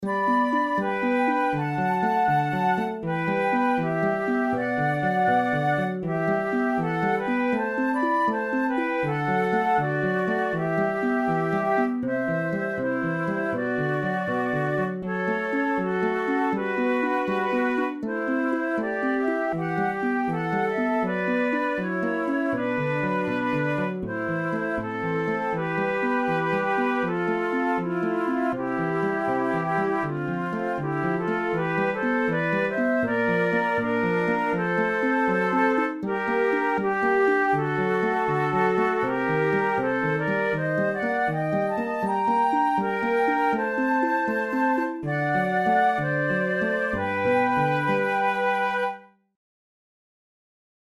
for two flutes and harp
Categories: Contemporary Difficulty: easy